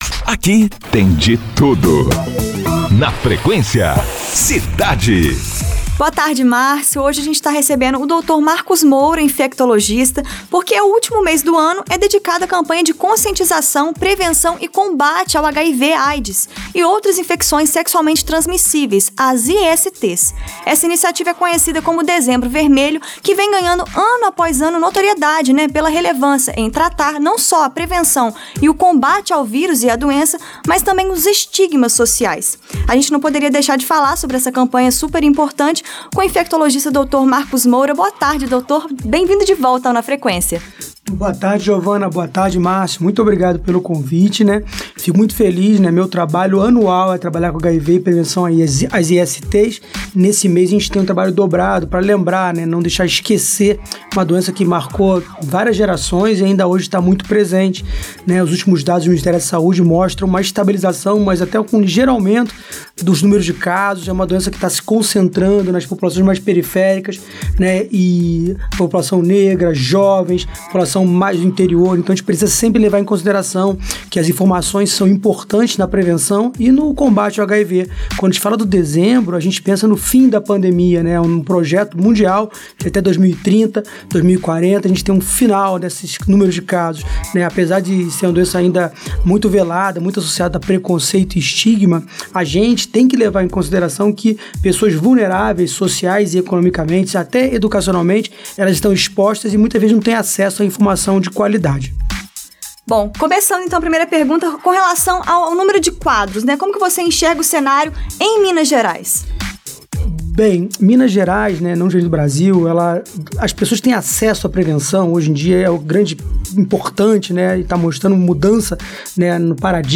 Nesta entrevista